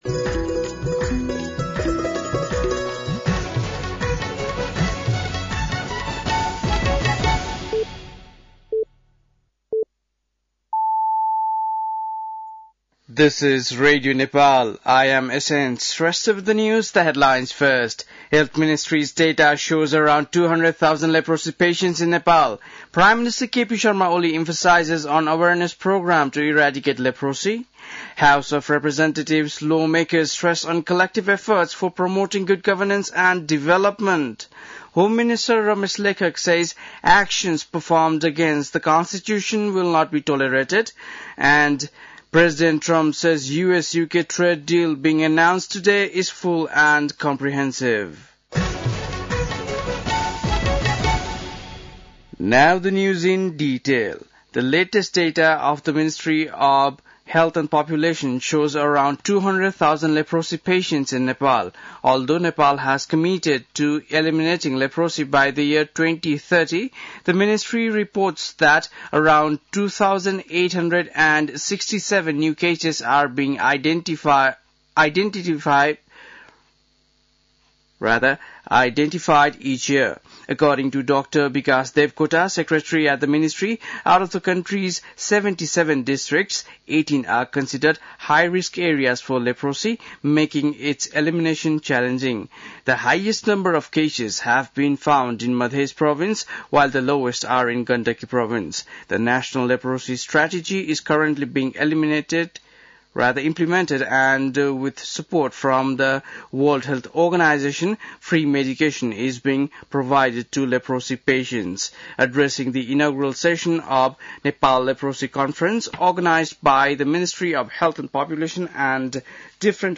बेलुकी ८ बजेको अङ्ग्रेजी समाचार : २५ वैशाख , २०८२
8-PM-English-NEWS-.mp3